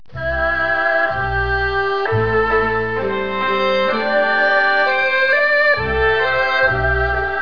erhu.wav